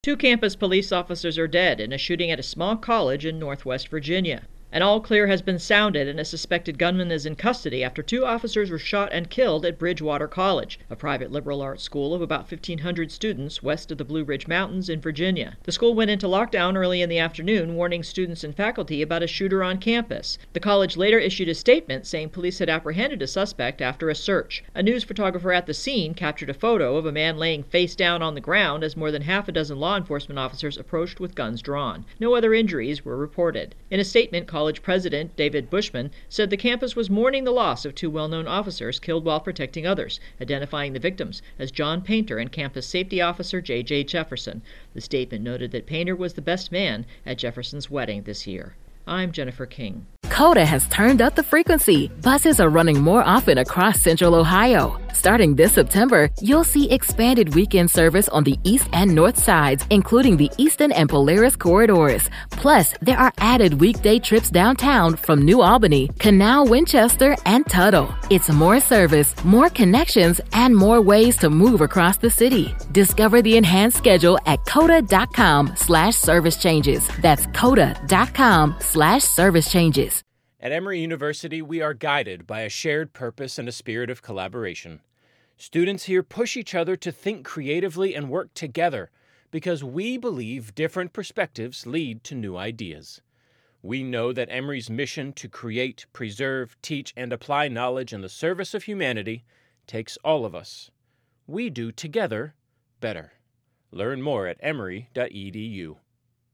Virginia College Lockdown intro and voicer